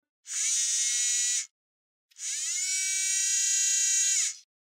Звуки зума
Увеличение и уменьшение